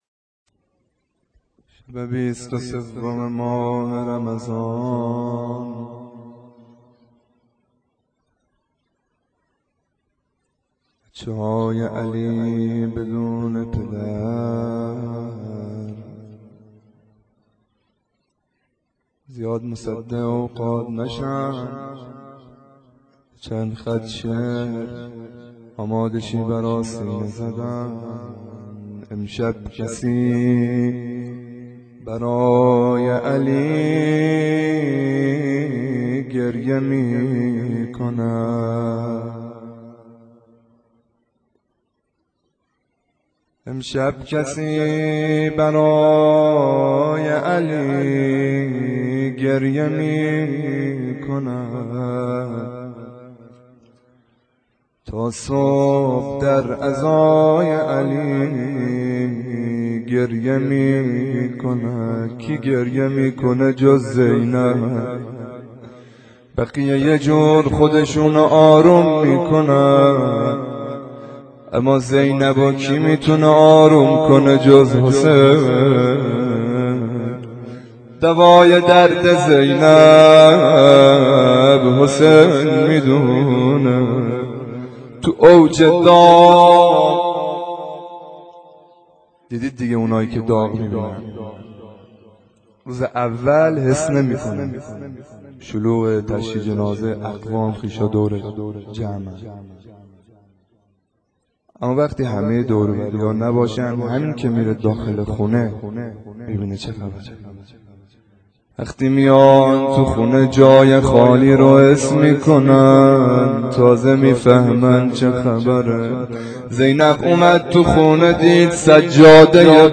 روضه شب 23 رمضان